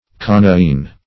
Coniine \Co*ni"ine\ (? or ?), n.